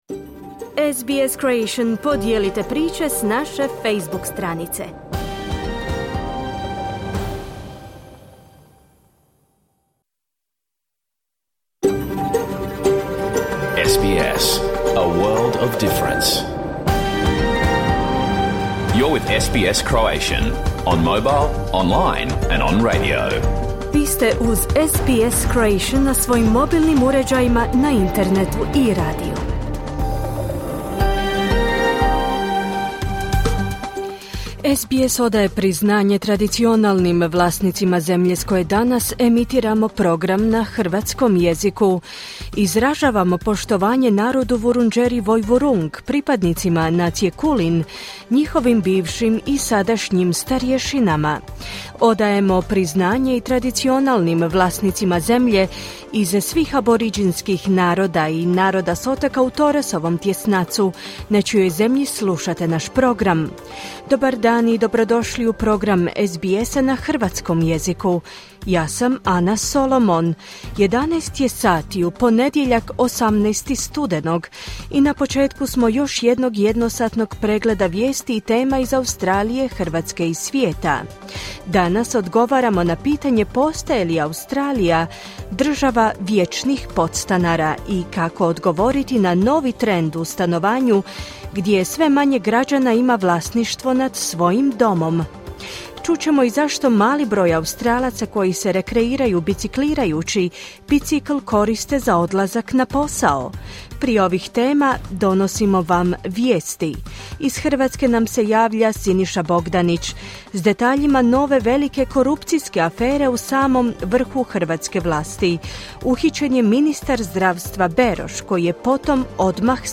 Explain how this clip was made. Vijesti i aktualnosti iz Australije, Hrvatske i svijeta. Emitirano uživo na radiju SBS1, u ponedjeljak, 18. studenog u 11sati, po istočnoaustralskom vremenu.